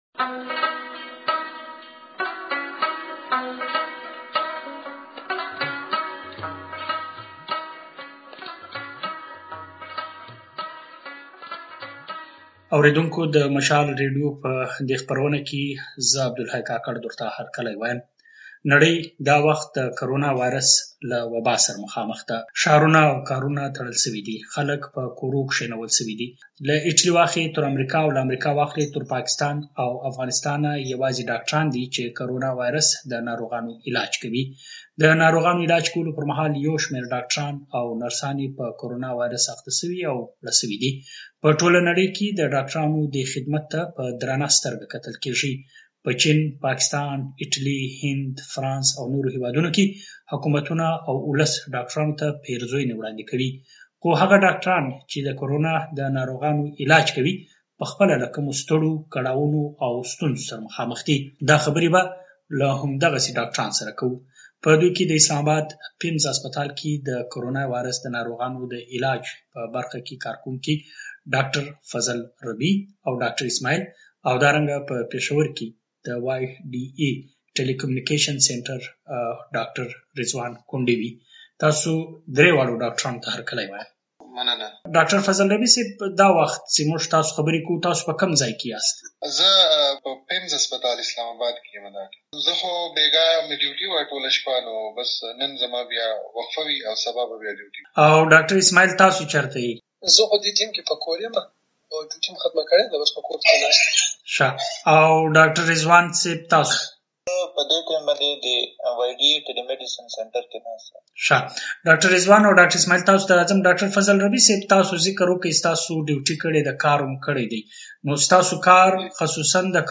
کورونا وايرس پخپله د دغو ډاګټرانو ژوند څومره اغېزمن کړی دی.؟ په دې اړه د مشال راډيو ځانګړی بحث واورئ.